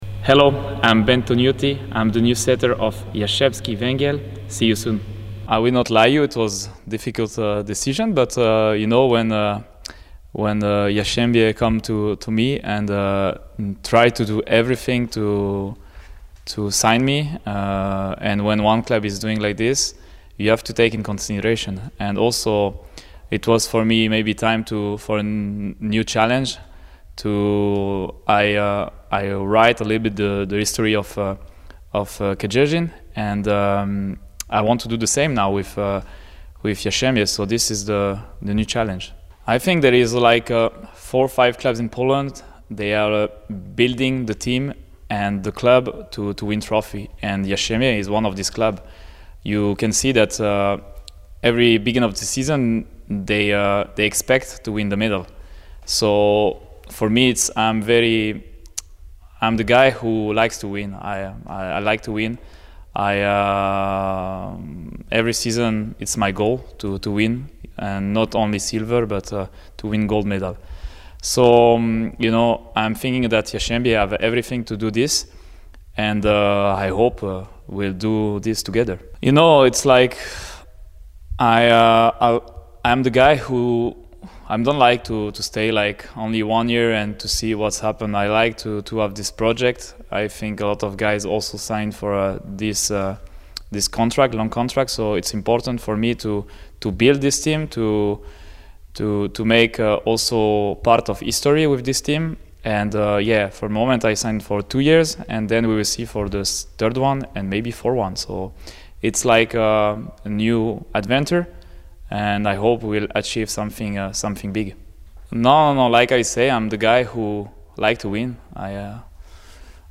Transferowy hit! Benjamin Toniutti rozgrywającym Jastrzębskiego Węgla! [Audio] Benjamin Toniutti pierwszy wywiad dla Jastrzębskiego Węgla